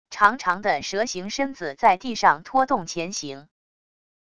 长长的蛇形身子在地上拖动前行wav音频